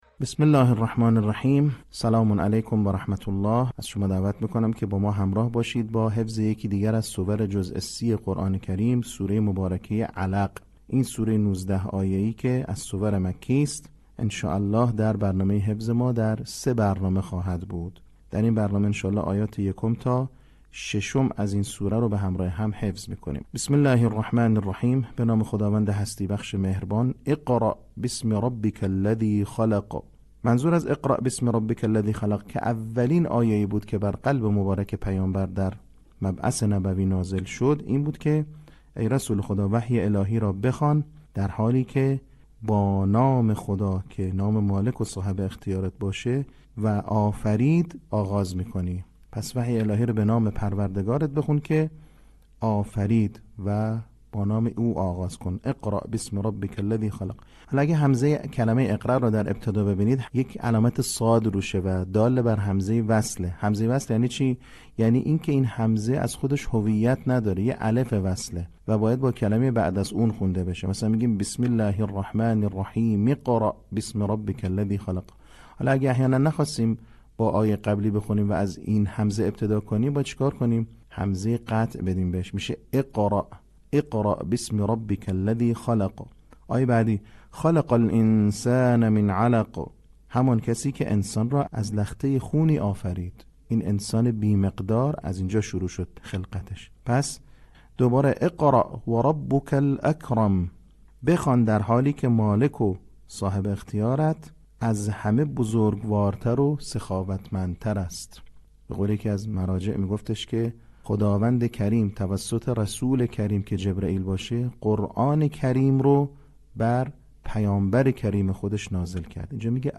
صوت | آموزش حفظ سوره علق